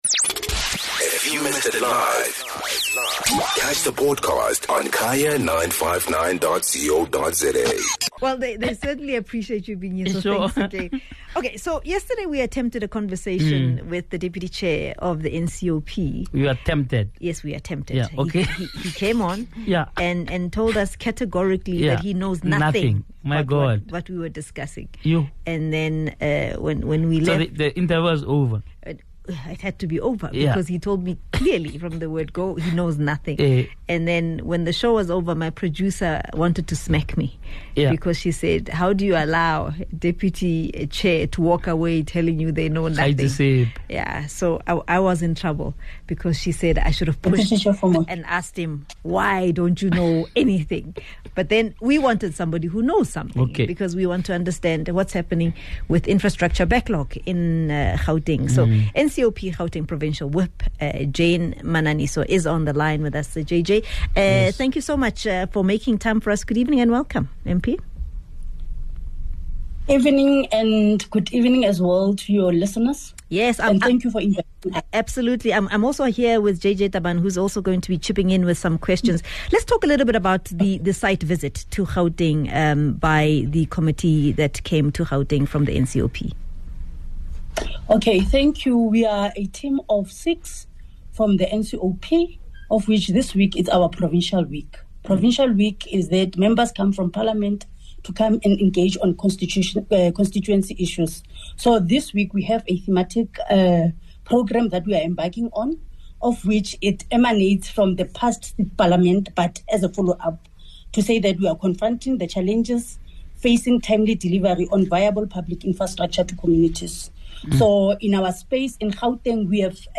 speak to NCOP Gauteng Provincial Whip, Jane Mananiso.